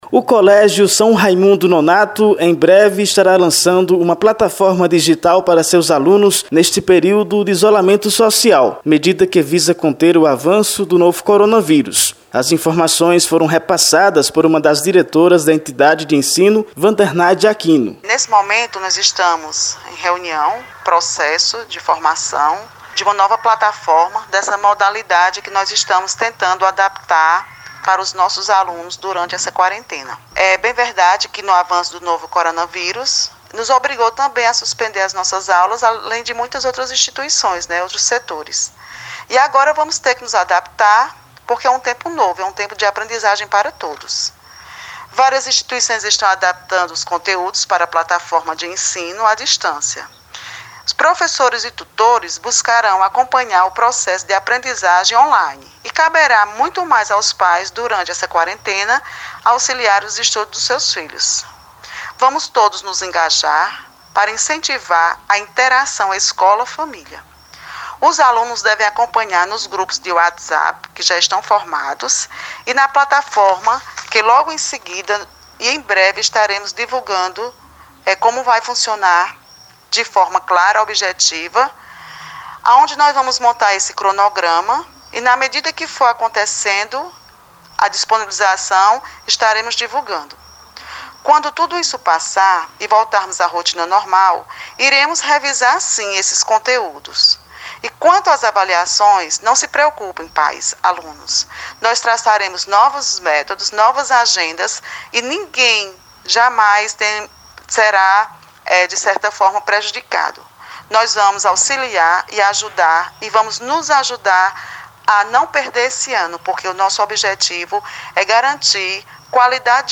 Acompanhe a reportagem no áudio: